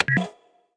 Menu Statsscreen Select Sound Effect
Download a high-quality menu statsscreen select sound effect.
menu-statsscreen-select.mp3